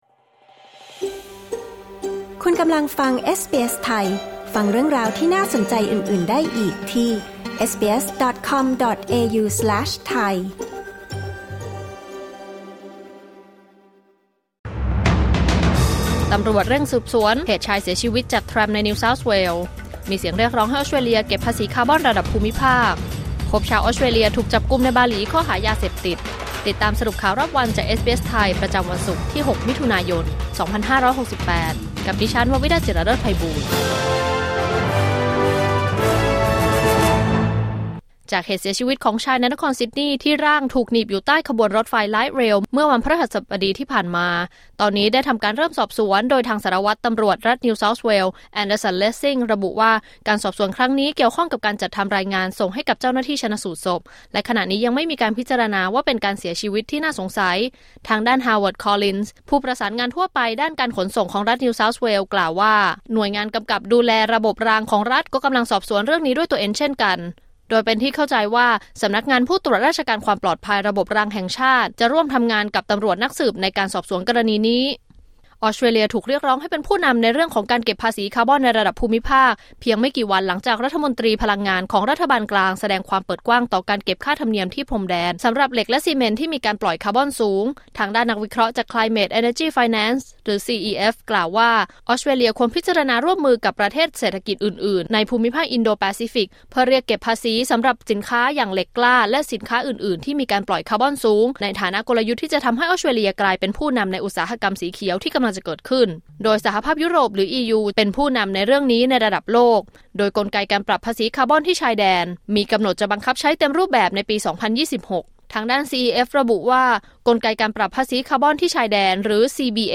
สรุปข่าวรอบวัน 6 มิถุนายน 2568